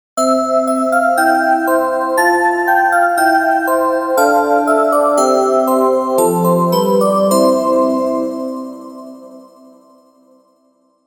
で、その楽譜を元にDAWに音符打ち込んで作ってみたMP3がこれ。